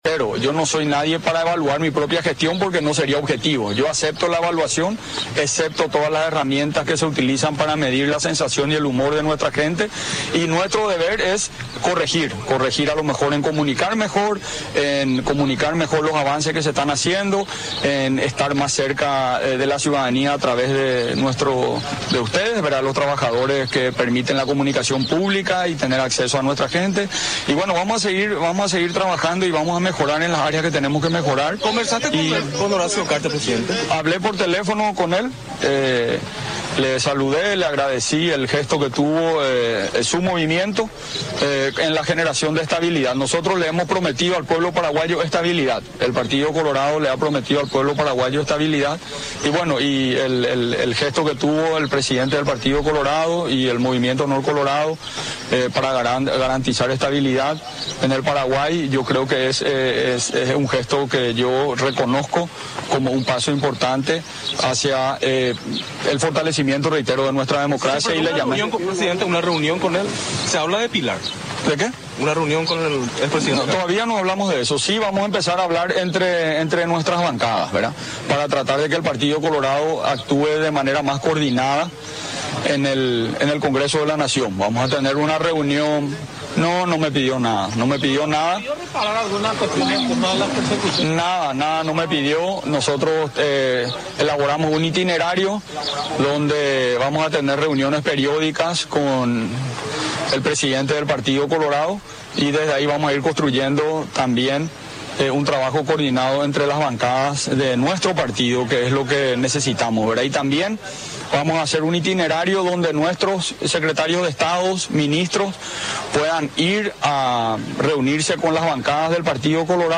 “Hablé con Horacio Cartes, es un gesto que tengo que reconocer”, expresó Abdo ante los medios tras el acto oficial festivo por la fundación de Asunción, celebrado en el jardín del Palacio de López.